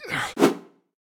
slash_A_pain.ogg